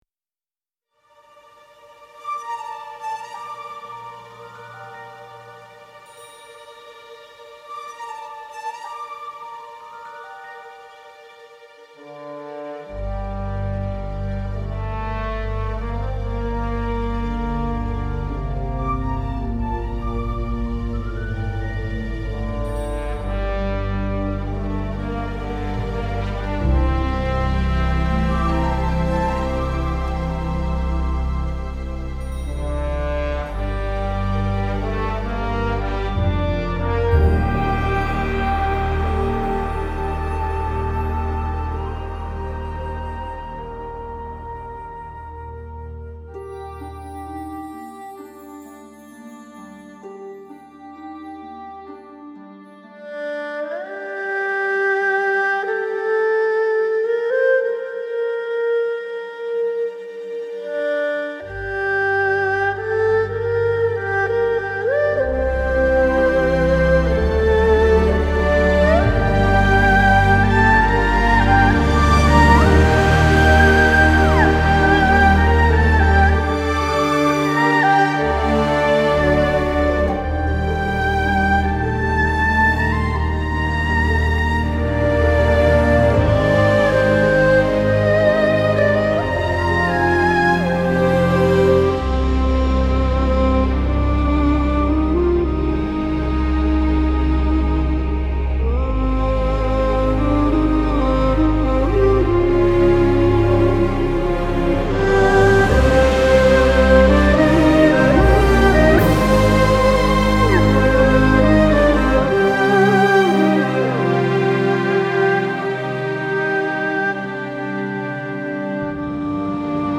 آرامش بخش , الهام‌بخش , بومی و محلی , ملل